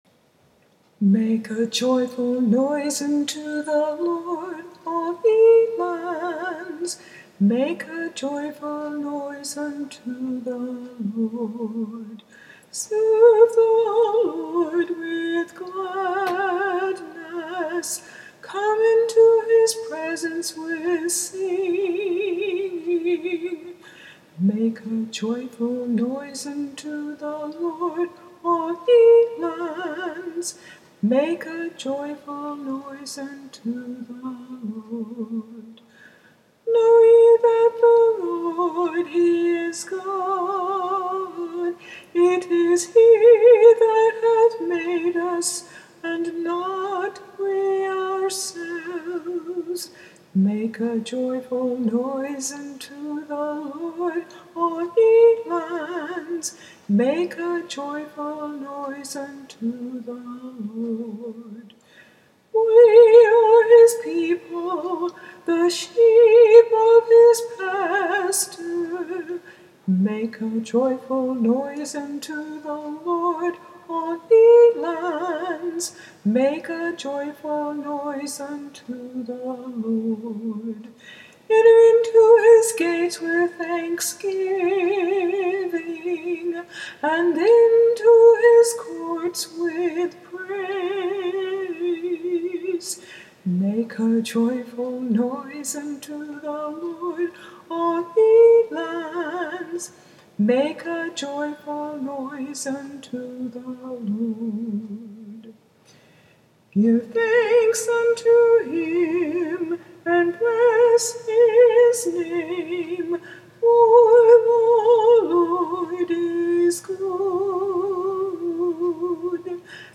In the meantime, here is Psalm 100, vocal without accompaniment.